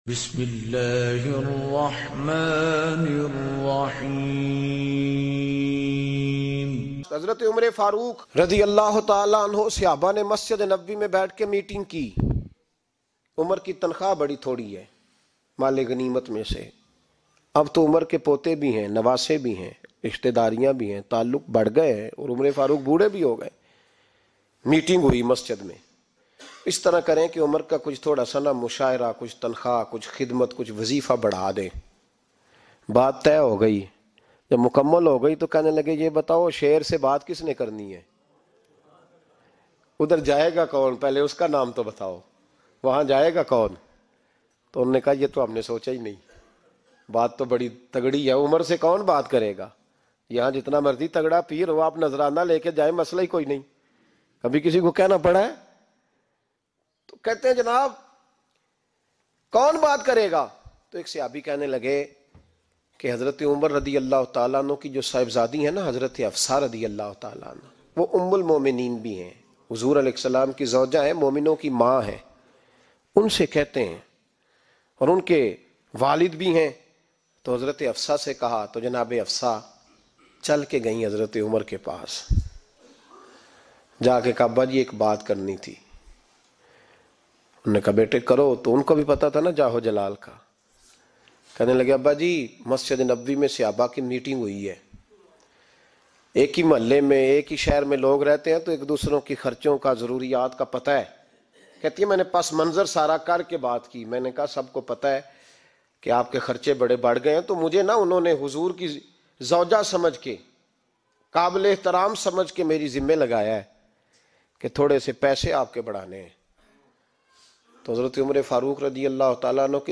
Hazrat Umar Ka Rula Deny Wala Waqia Bayan